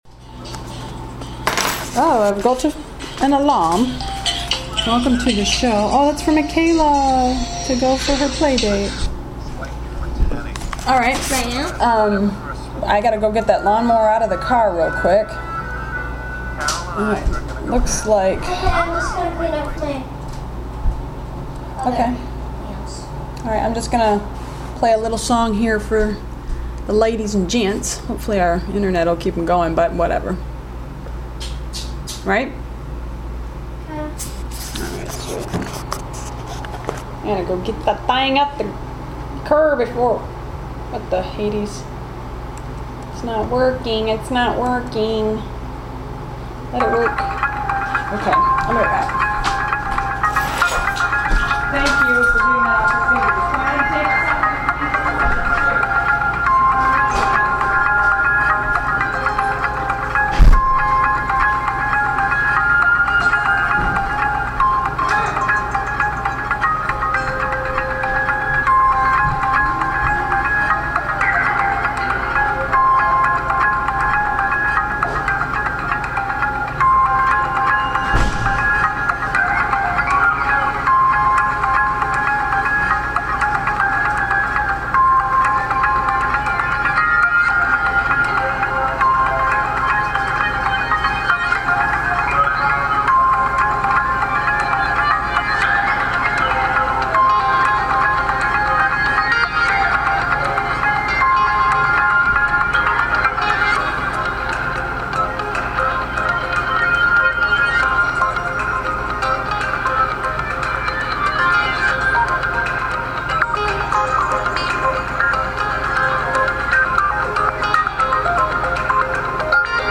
Talk Show Episode, Audio Podcast, New_Clear_Visions and Courtesy of BBS Radio on , show guests , about , categorized as